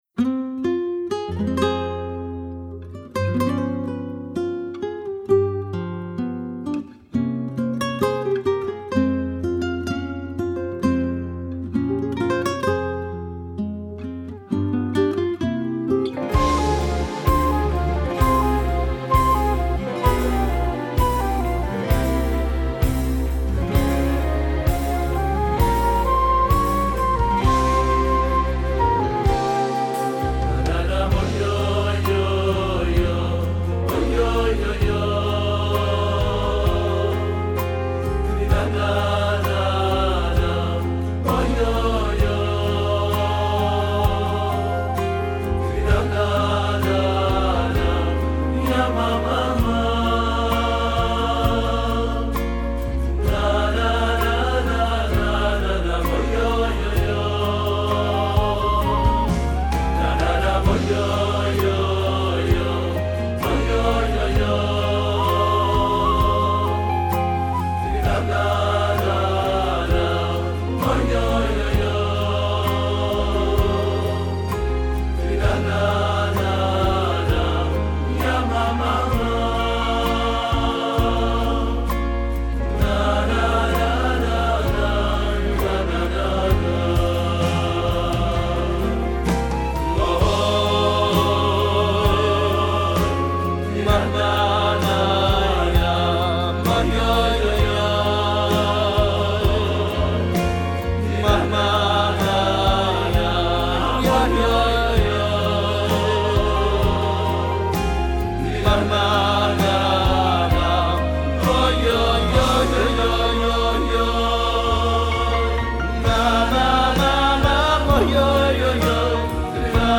בשילוב מקהלה מורחבת באווירה של בית כנסת